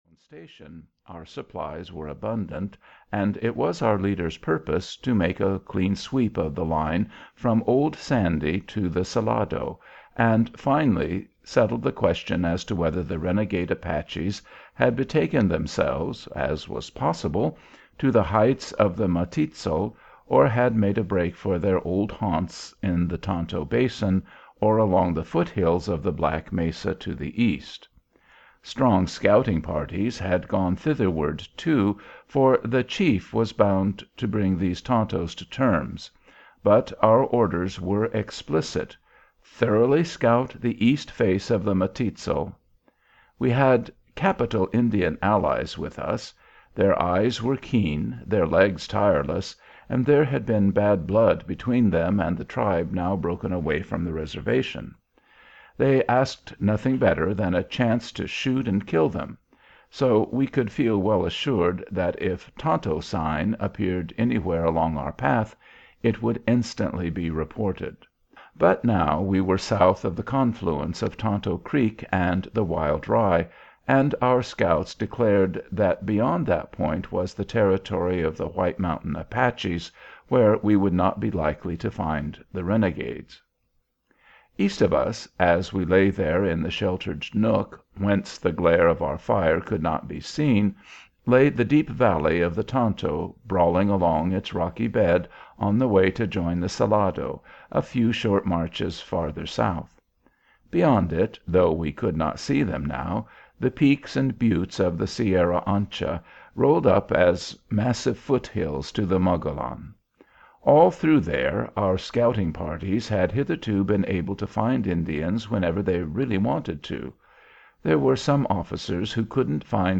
Audio knihaStarlight Ranch and Other Stories of Army Life on the Frontier (EN)
Ukázka z knihy